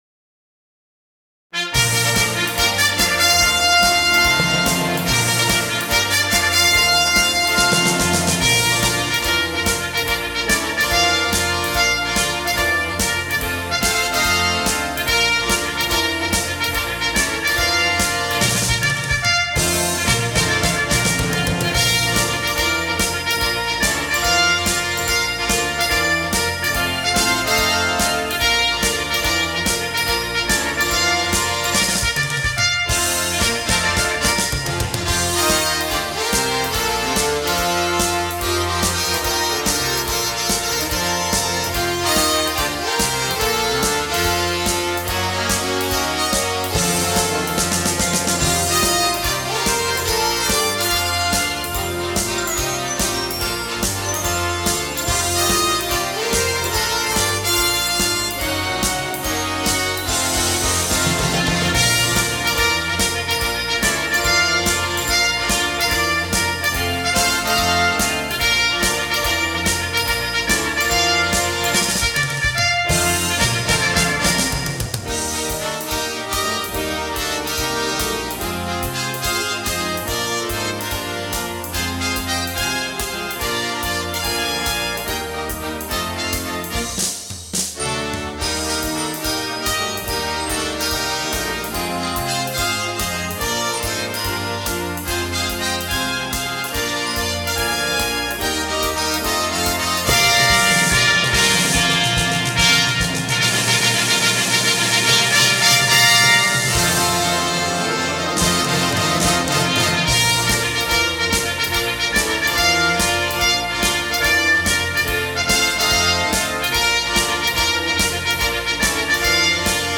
Flute
Bass Clarinet
Bb Bugles / Trumpets
Bass Trombone
Euphonium
Tuba
Timpani
Drum Kit
Glockenspiel
Bass Guitar